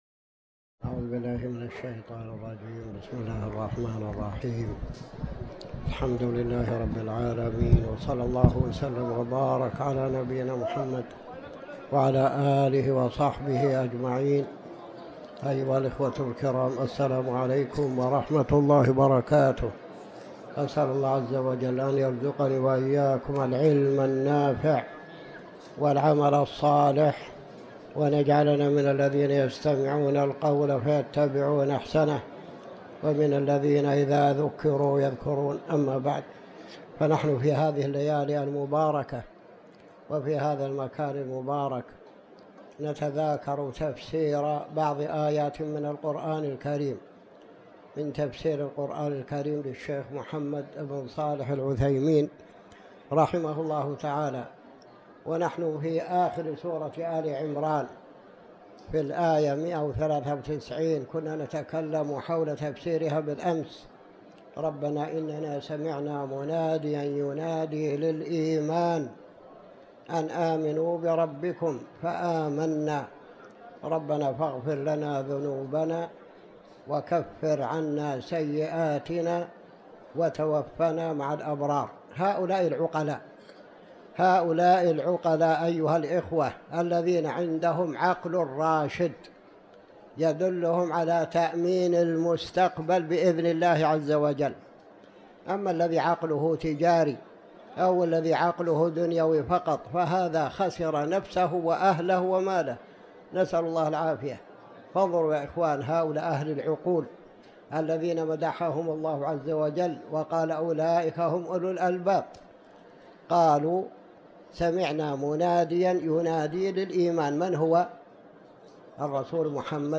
تاريخ النشر ٢٤ رجب ١٤٤٠ هـ المكان: المسجد الحرام الشيخ